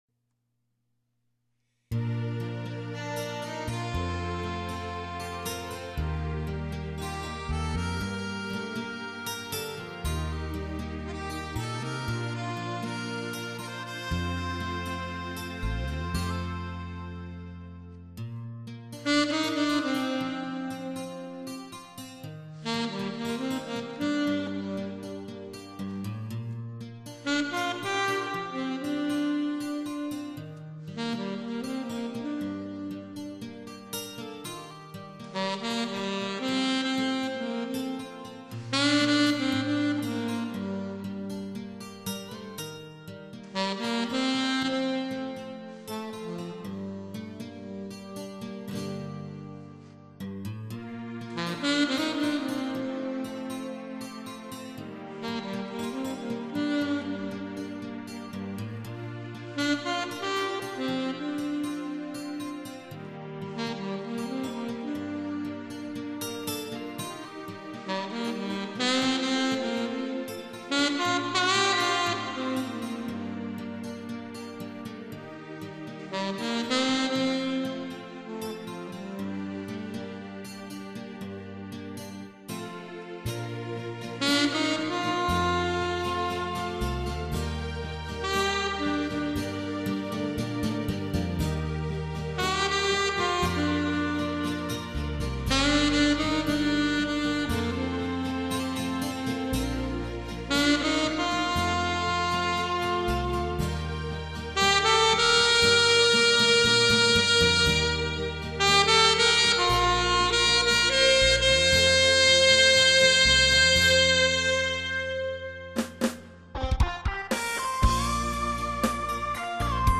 약간 신세대틱한 노래입니다.